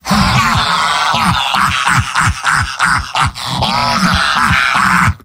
Giant Robot lines from MvM. This is an audio clip from the game Team Fortress 2 .
Demoman_mvm_m_laughlong01.mp3